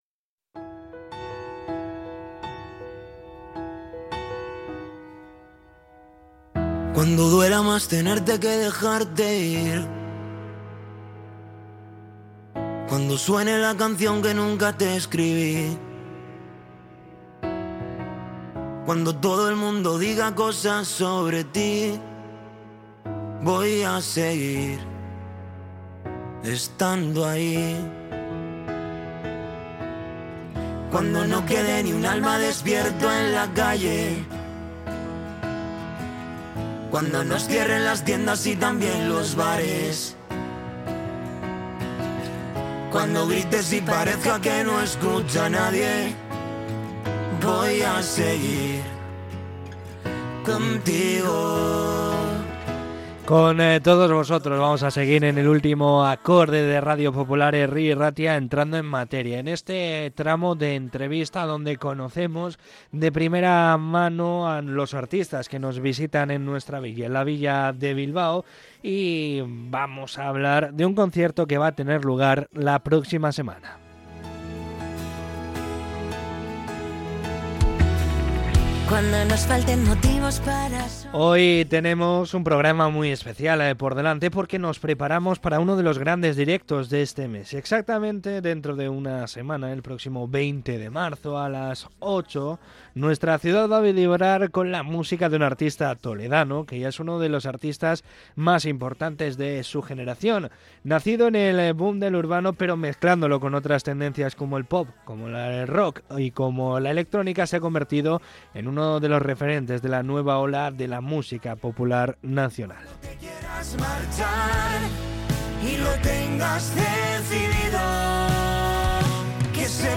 En una entrevista con El Último Acorde, el cantante toledano reflexiona sobre la vorágine de la industria musical